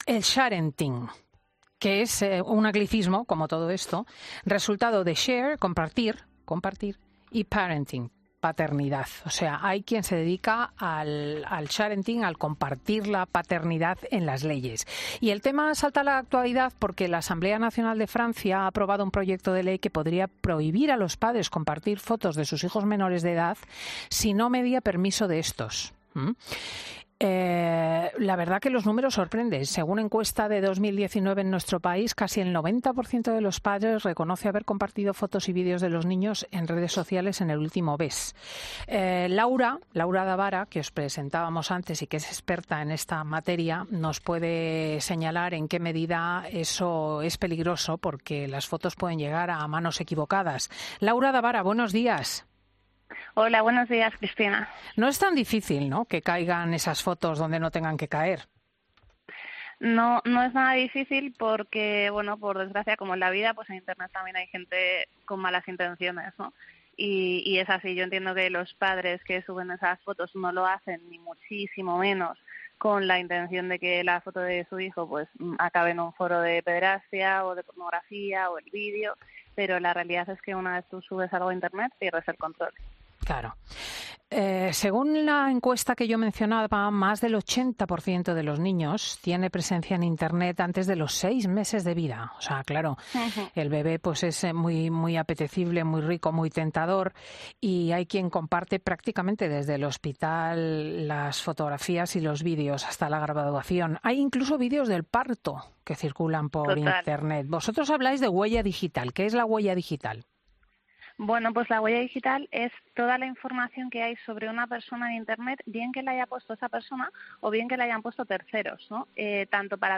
En Fin de Semana preguntamos a los expertos que, una vez más, alertan: No todos los que dan con las fotos de los menores lo hacen con una mirada limpia.